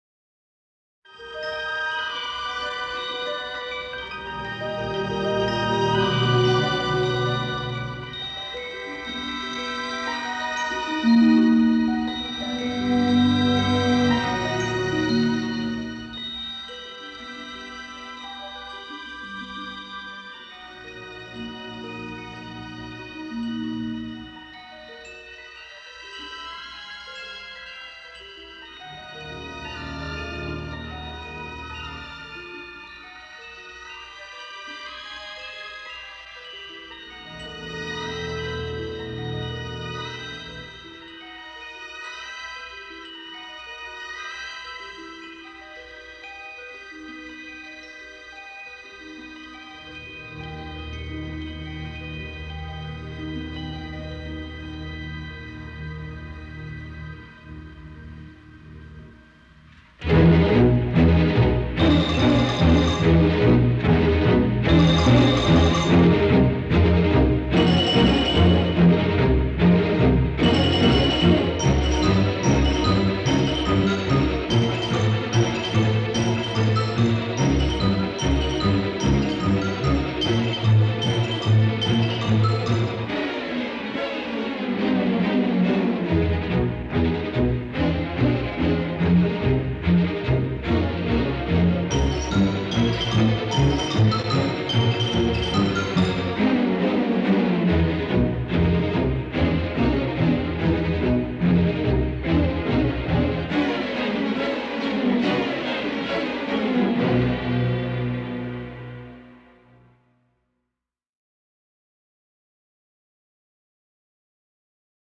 irascibilidad melódica